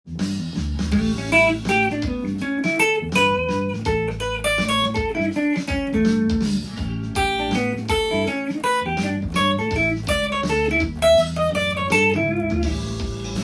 ギターソロでかなり使える【トライアド】
A7のコード上でGのトライアドを使うと
サウンド的にA/Gというオンコードの響きになるので